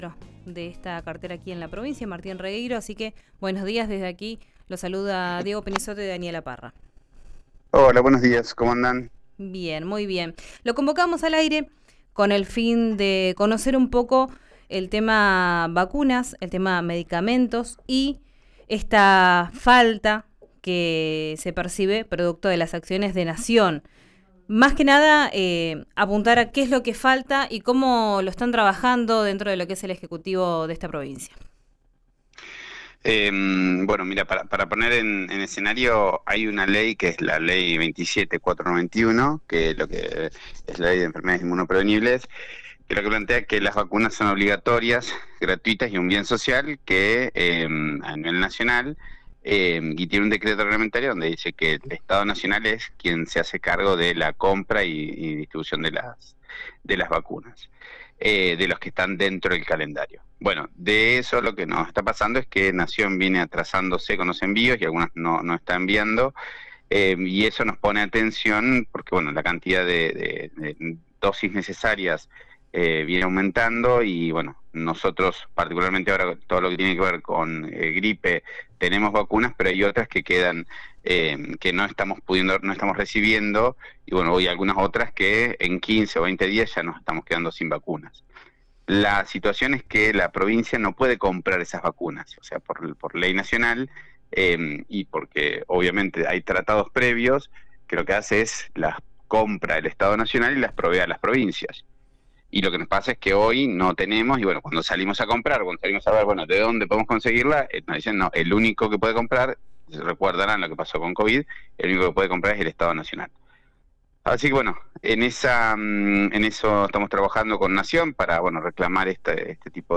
Martín Regueiro habló con RÍO NEGRO RADIO y dijo: 'Necesitamos que Nación las envíe'.
Martín Regueiro en RÍO NEGRO RADIO: